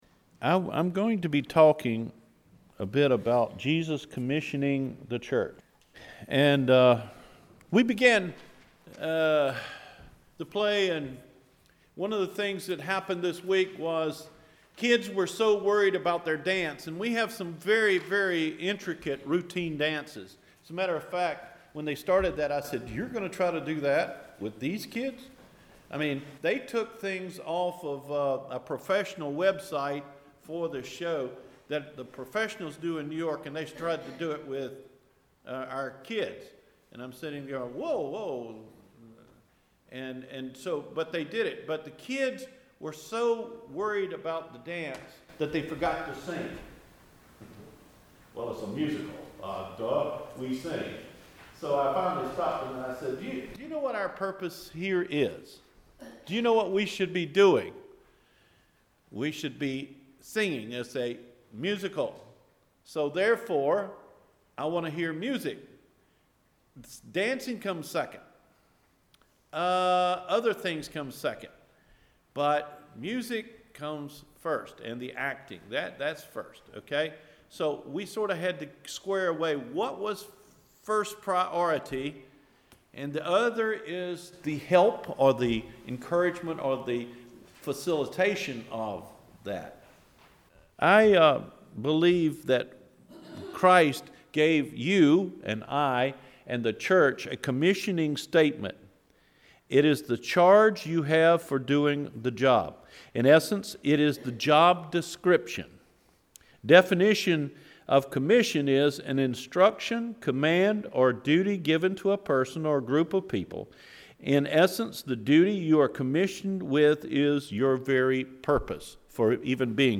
The Commission of the Church – April 15 Sermon